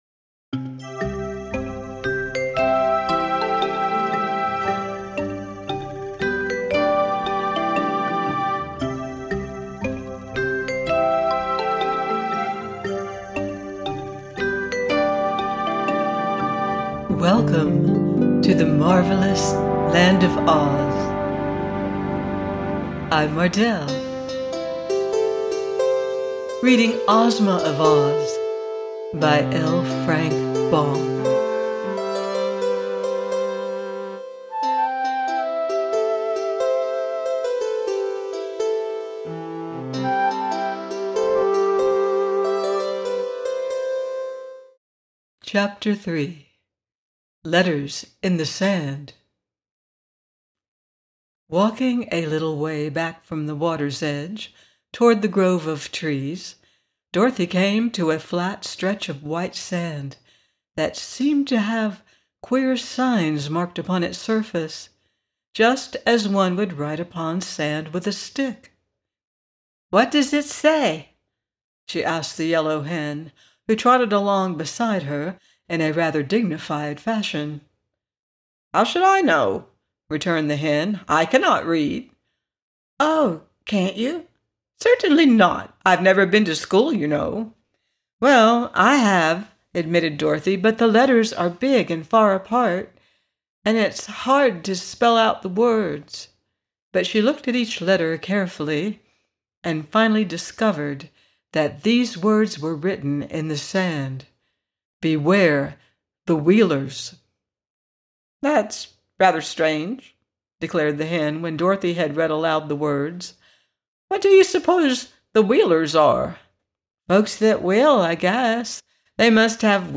Ozma Of Oz – by L. Frank Baum - audiobook